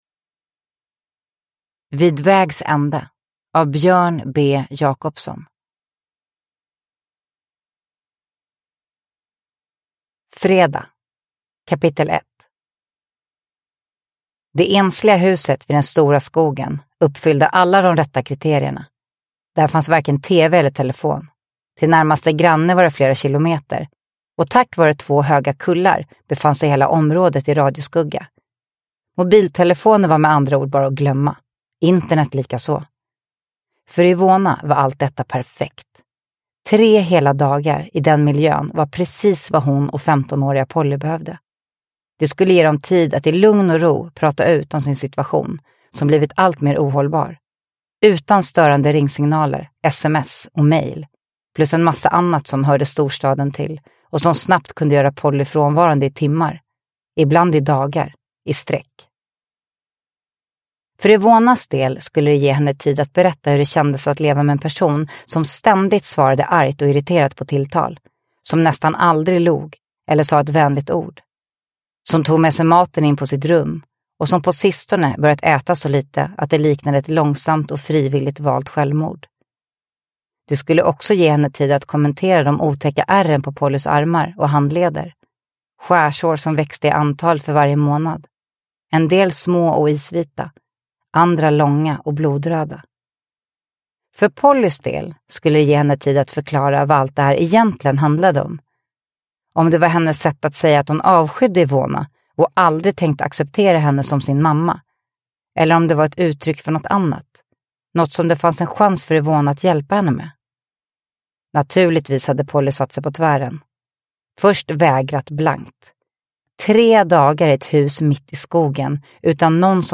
Vid vägs ände – Ljudbok – Laddas ner
Uppläsare: Sanna Bråding